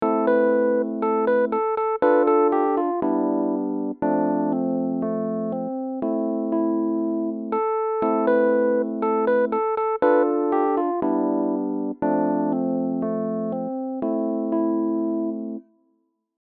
Un clic plus tard, nos quatre mesures vides se sont remplies de notes et nous avons entendu ceci:
yesterchelle-midi.mp3